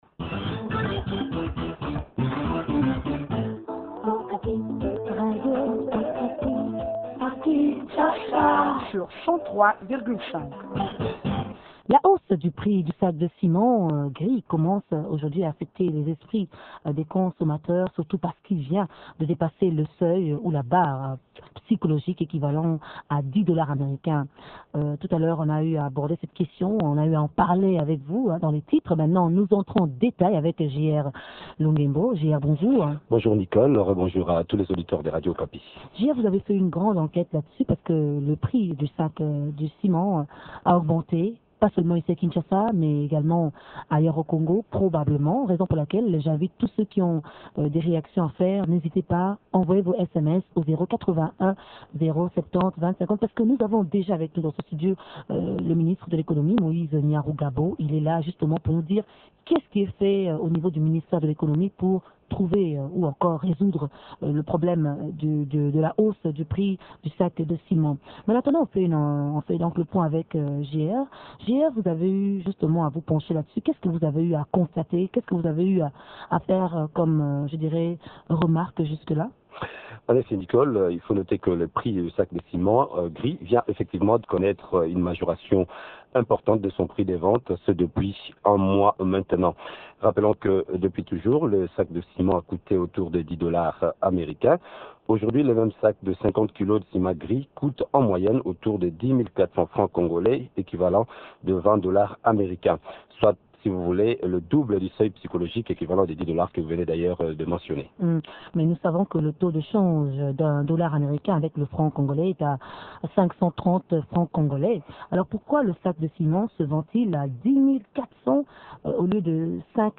L?essentiel de l?entretien dans cet élément.